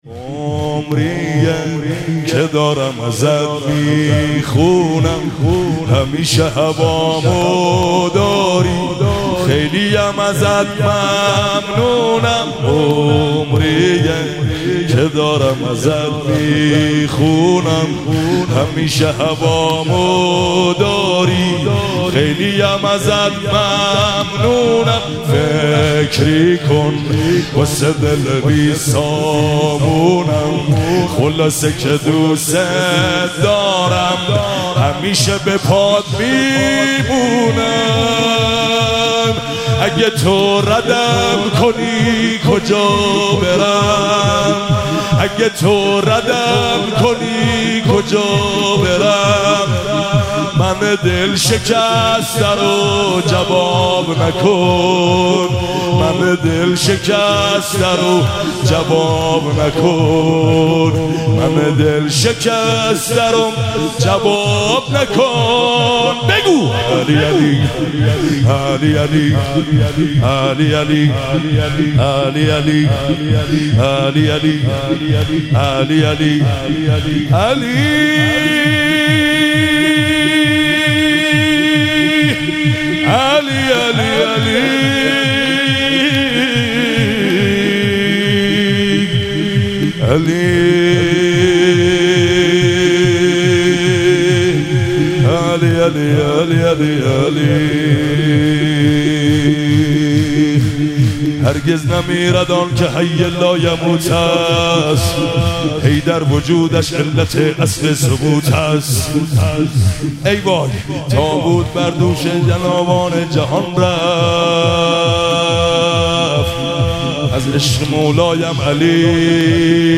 شور- عمریه که دارم ازت می خونم
مراسم جشن شب اول ویژه برنامه عید سعید غدیر خم 1444